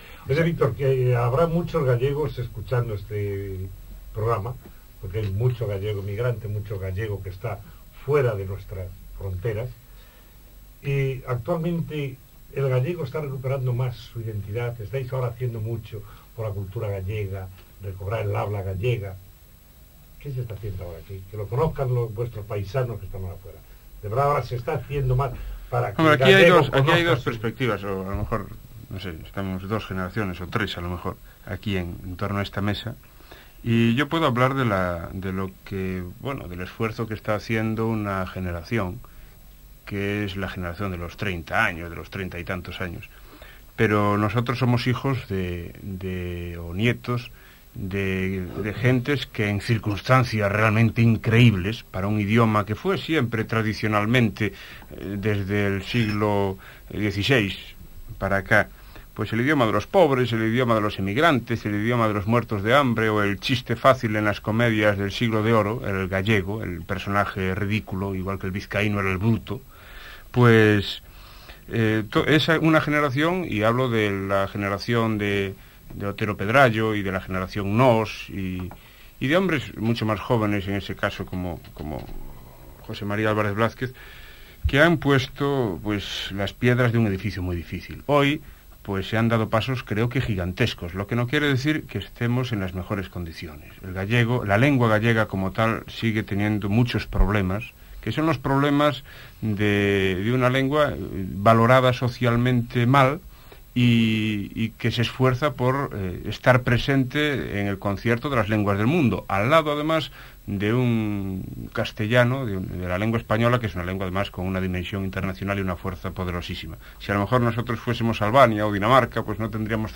1982 (?). Fragmento do programa "Tertulia", de Radio Exterior de España, emitido desde os estúdios de Radio Cadena Española em Vigo